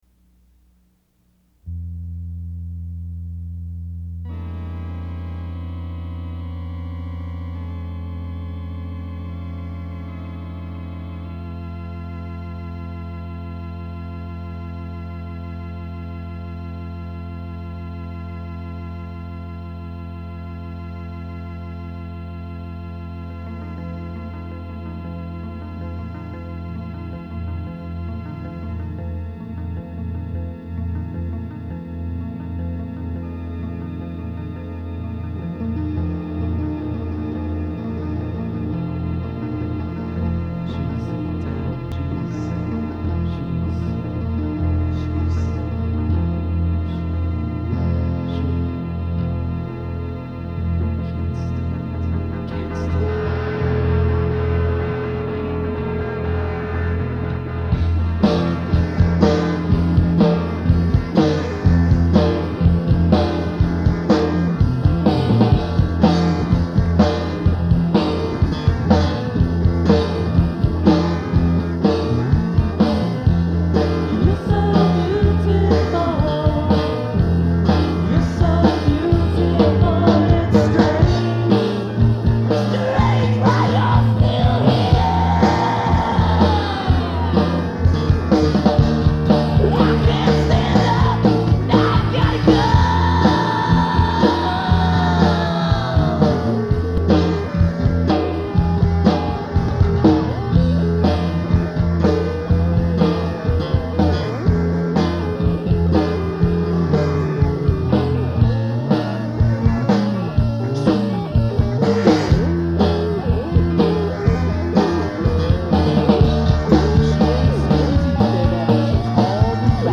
added in some psychedelic and noise elements to it